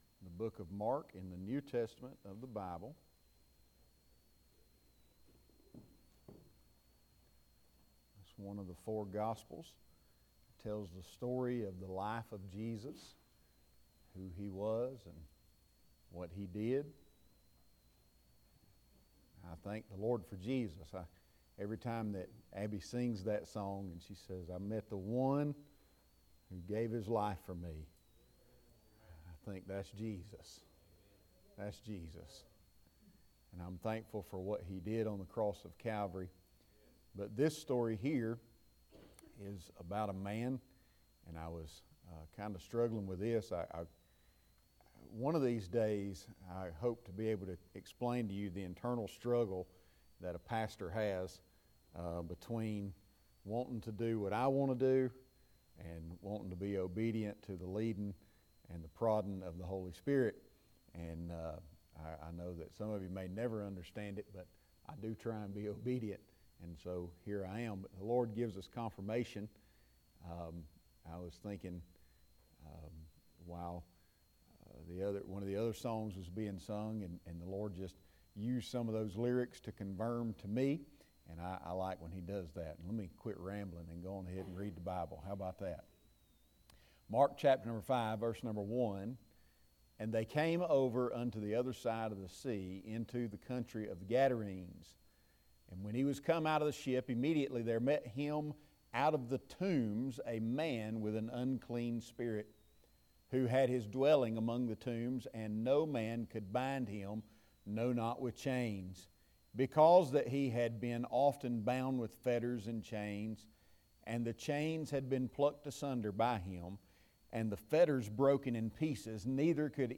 Sermons | Gateway Baptist Church